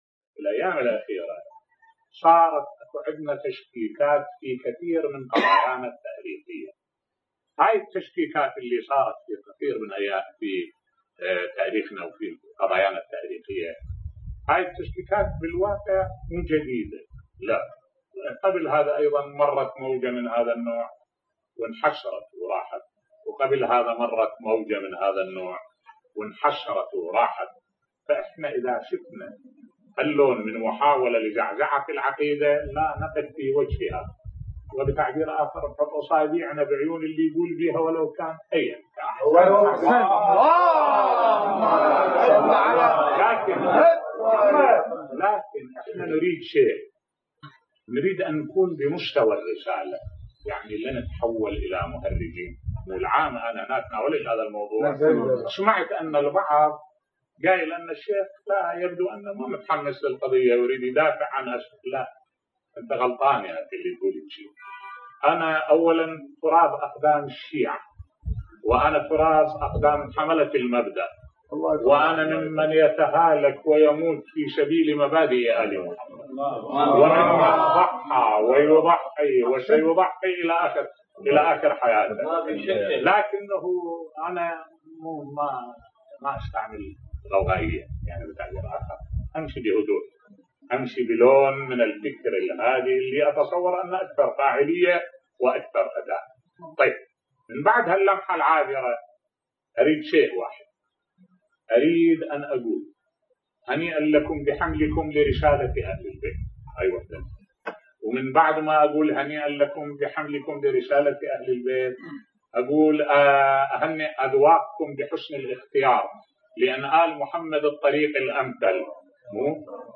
ملف صوتی رد الشيخ احمد الوائلي على من يتهمه في عقيدته ويسيء اليه بصوت الشيخ الدكتور أحمد الوائلي